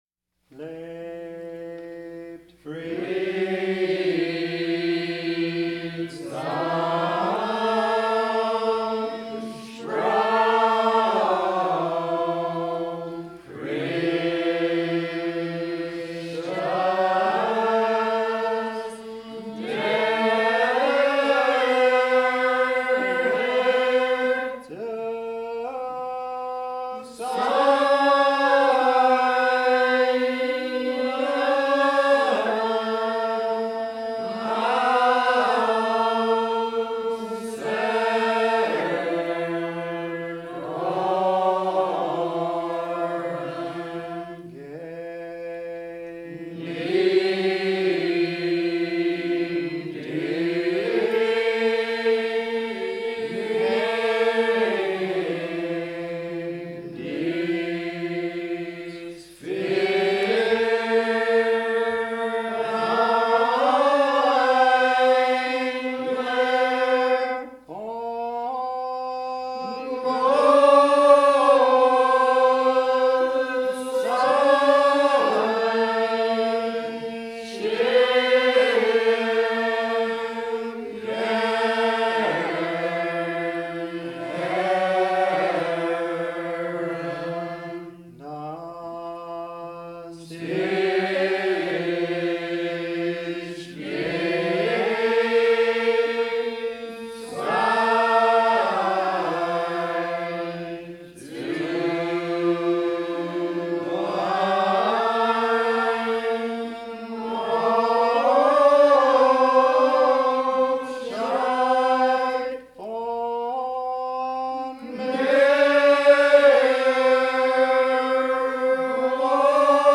Lebt Friedsom; Authentic Amish Church song